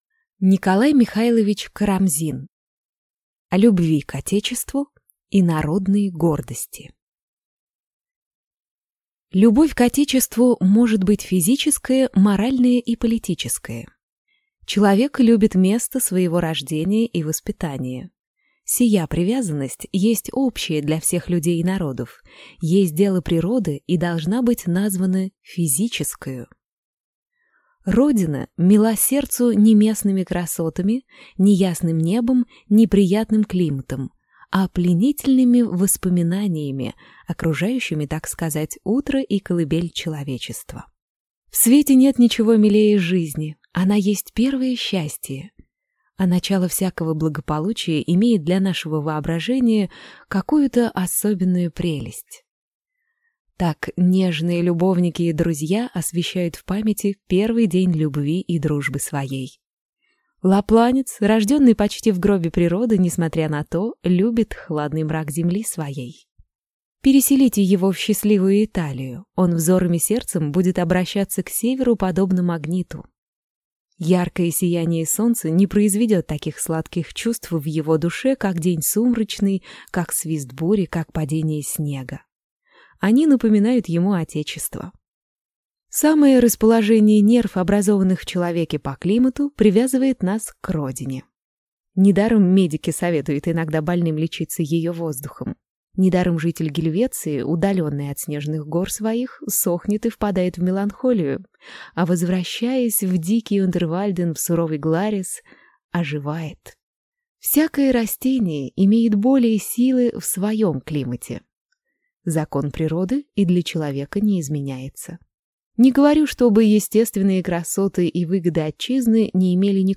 Аудиокнига О любви к отечеству и народной гордости | Библиотека аудиокниг